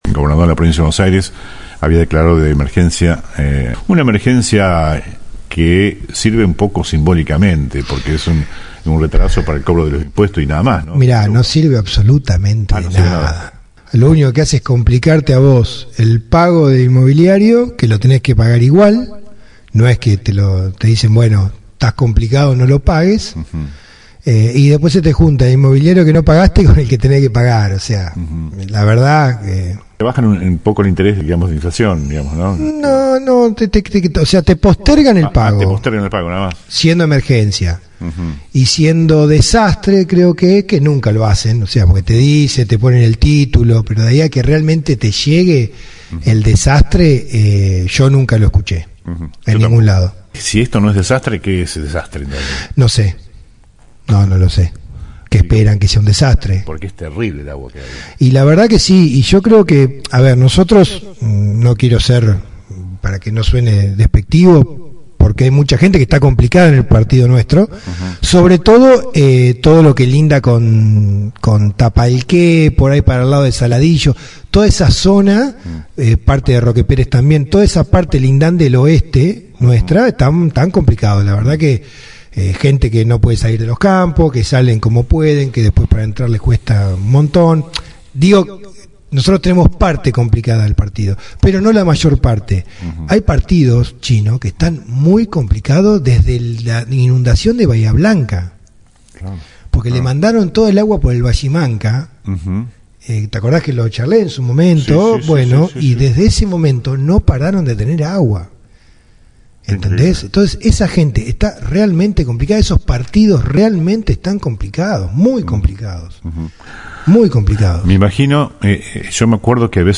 al piso de LA RADIO.